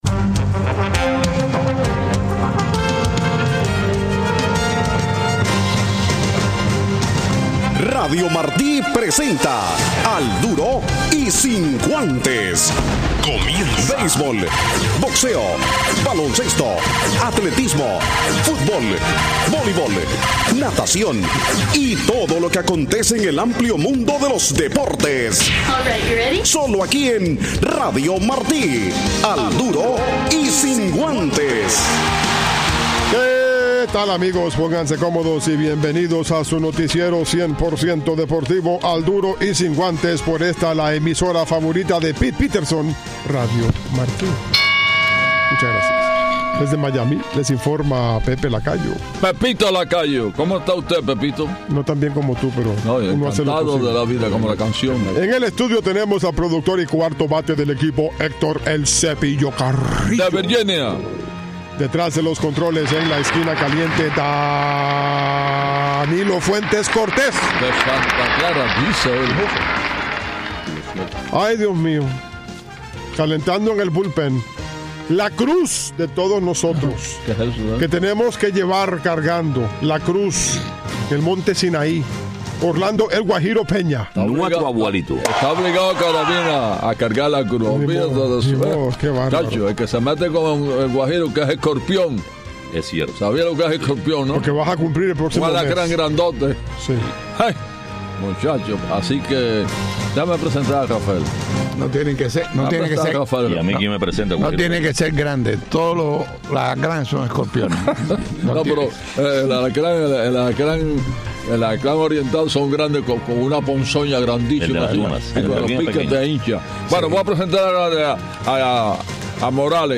También hubo cartas leidas, más sobre la pelota, no se pierdan este programa deportivo!